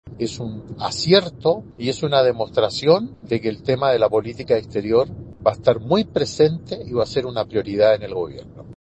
Ante esto, el presidente de la Comisión de Relaciones Exteriores del Senado, Iván Moreira, sostuvo que esta visita da muestra de que la política exterior será prioridad en la próxima administración.